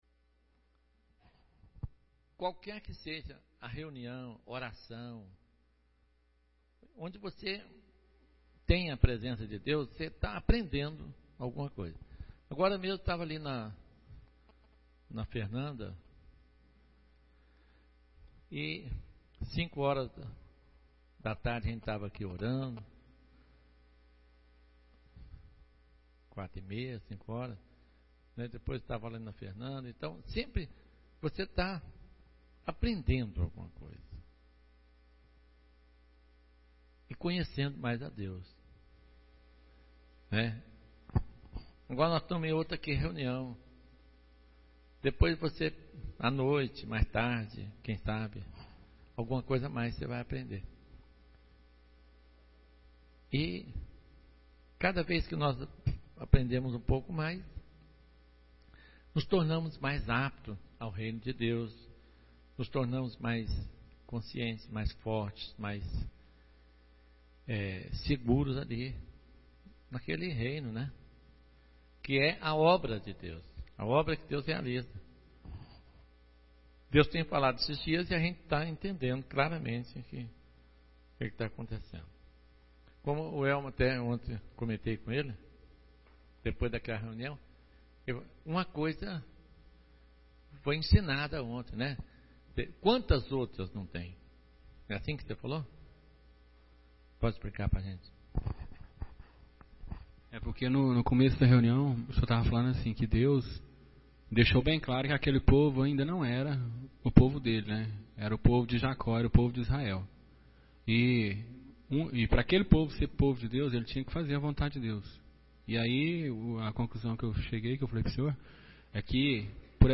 Reunião de Casais – 2006-07-06 – Uma casa dividida contra si não pode subsistir.